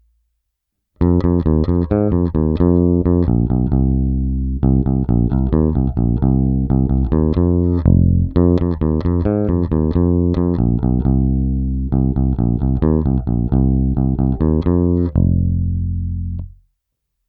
V tomto případě jsem dostal obě basy vybavené ocelovými hlazenými strunami.
Nahrávky jsou provedeny rovnou do zvukovky a dále kromě normalizace ponechány bez úprav.